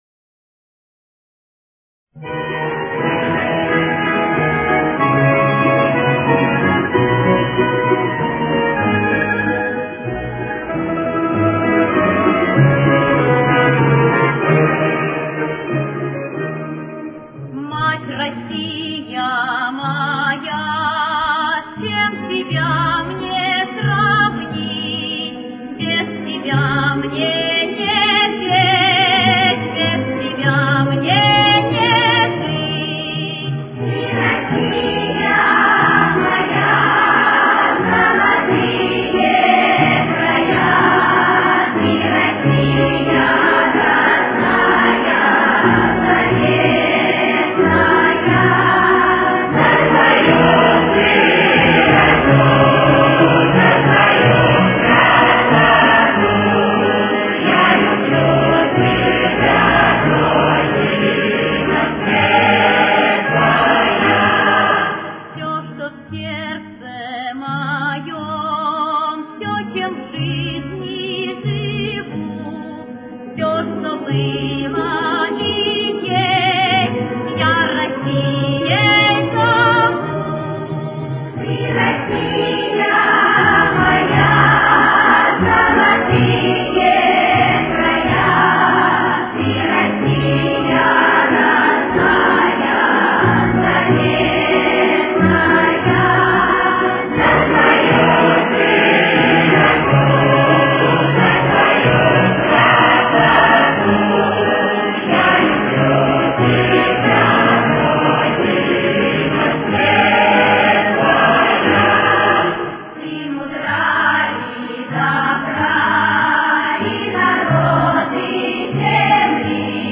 Соль-диез минор. Темп: 92.